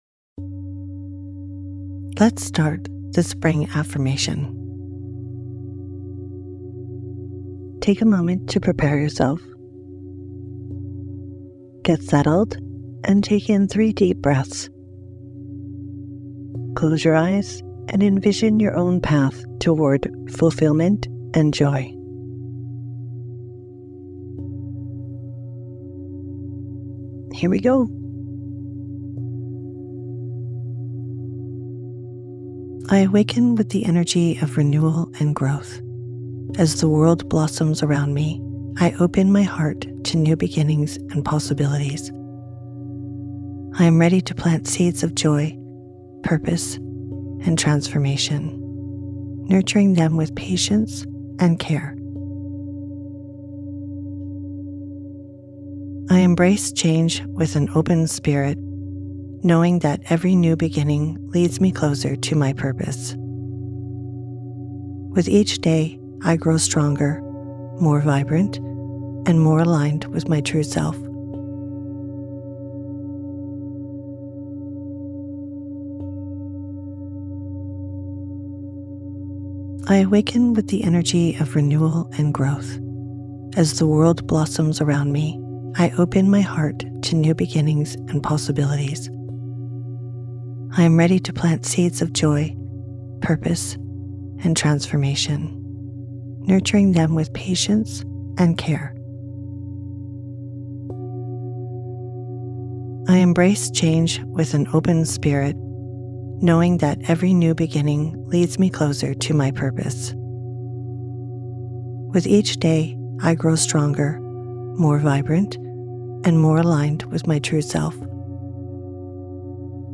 Sleep versions feature the affirmation repeated three times, fostering repetition for deeper impact and greater benefits.